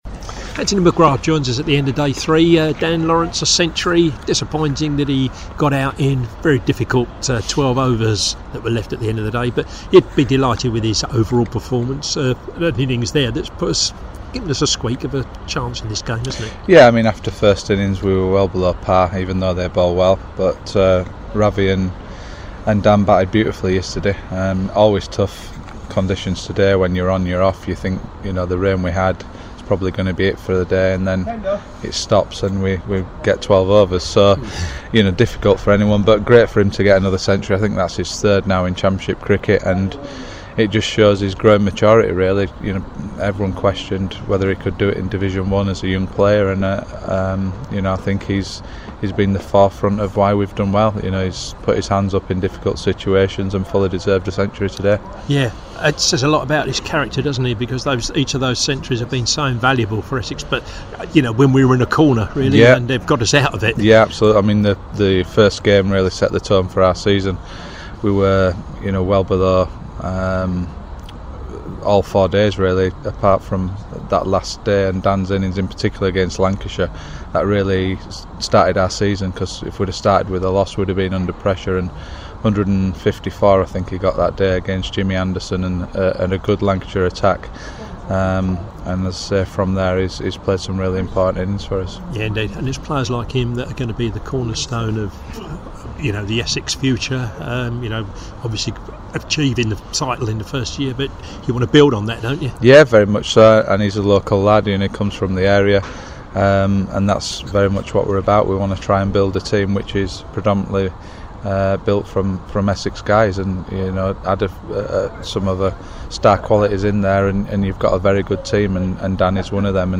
Assistant Head Coach, Anthony McGrath talks after play on Day Three against Hampshire as Dan Lawrence reaches three figures for Essex.